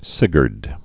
(sĭgərd)